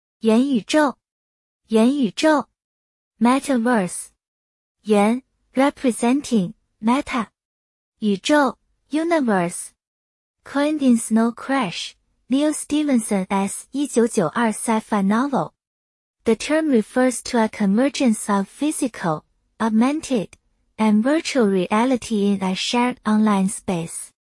yuán yǔ zhòu